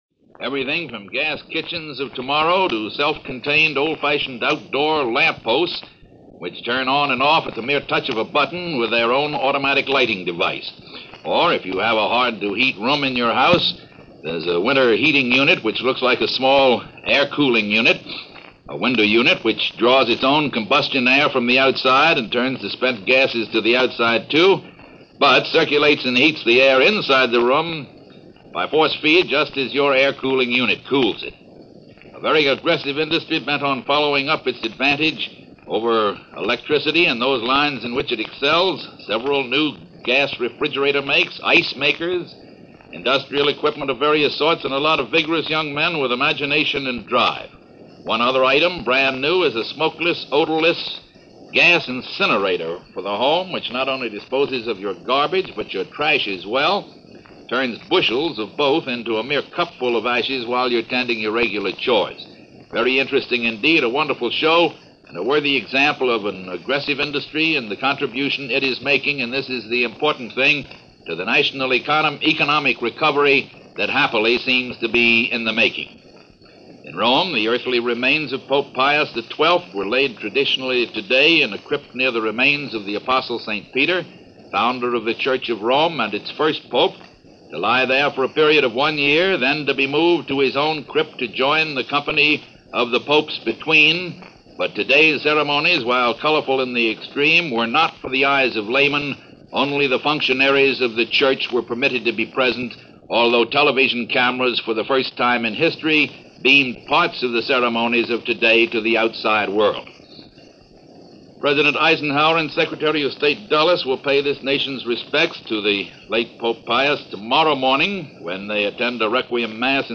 October 13, 1958 - Burying a Pope - Reviving A Red Scare - news for this day in 1958 as reported by Fulton Lewis Jr. and The News.